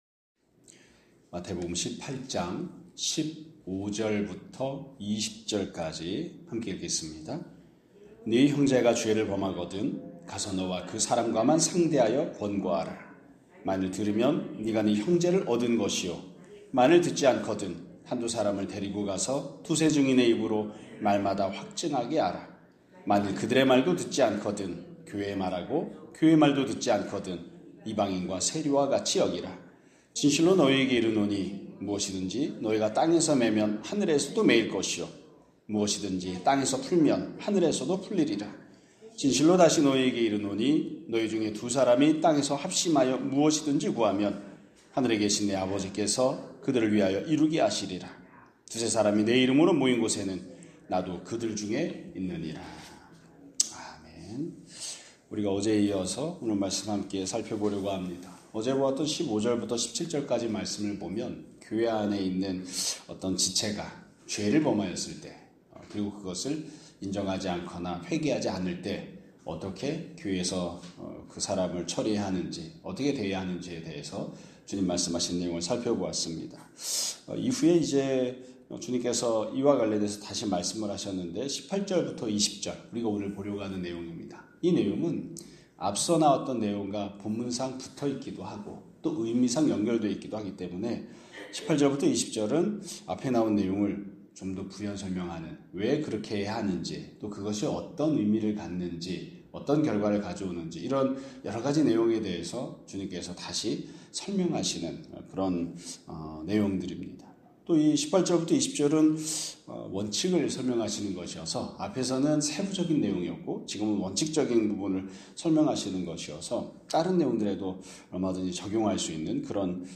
2025년 12월 11일 (목요일) <아침예배> 설교입니다.